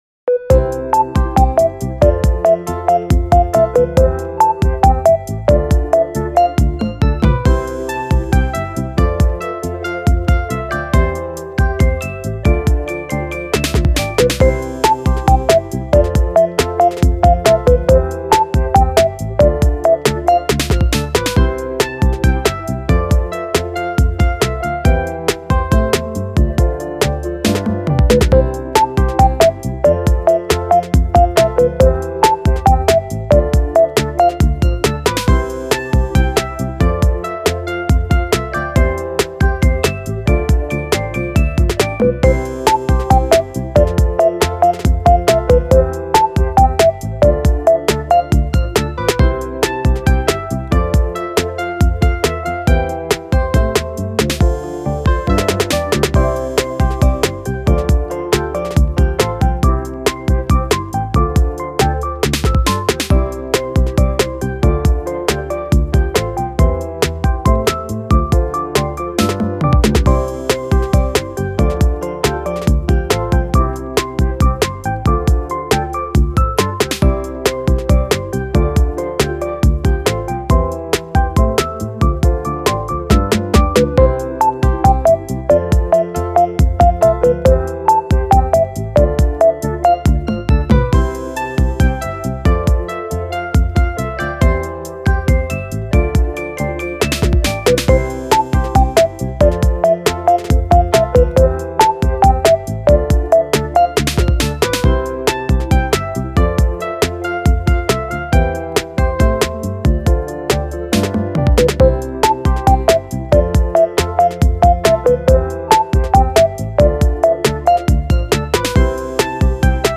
フリーBGM